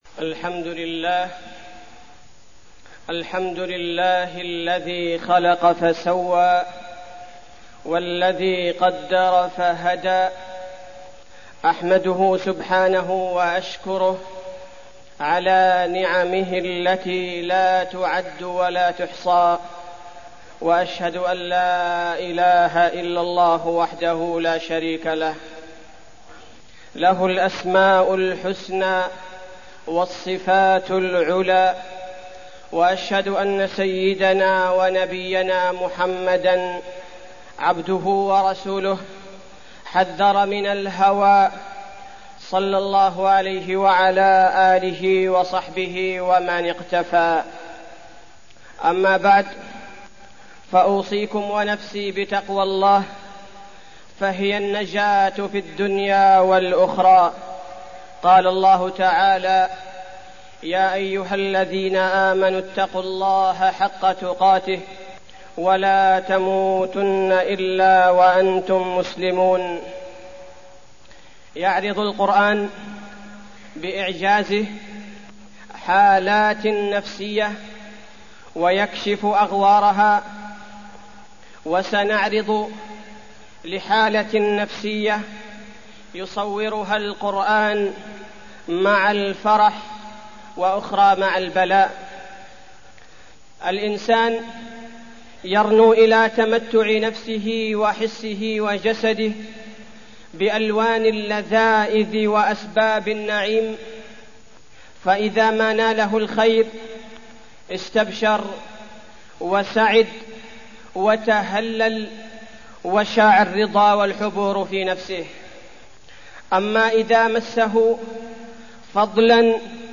تاريخ النشر ١٩ شعبان ١٤١٨ هـ المكان: المسجد النبوي الشيخ: فضيلة الشيخ عبدالباري الثبيتي فضيلة الشيخ عبدالباري الثبيتي هوى النفس